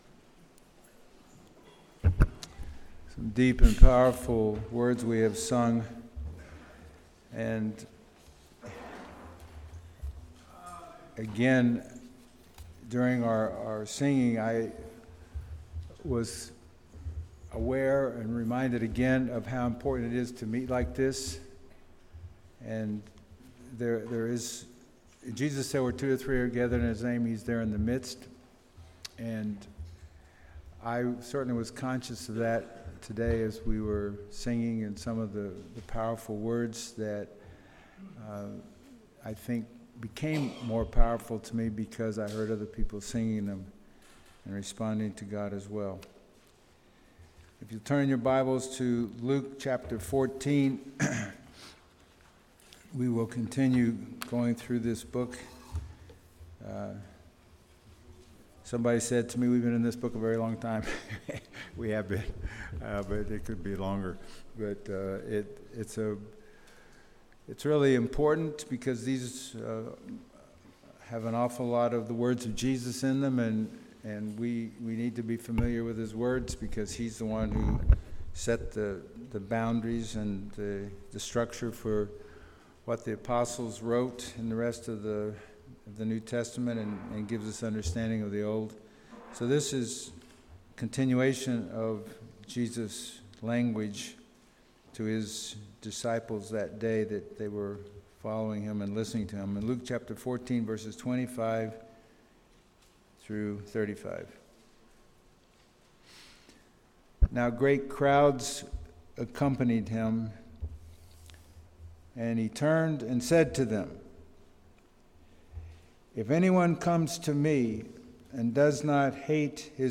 Passage: Colossians 1:28 Service Type: Sunday Morning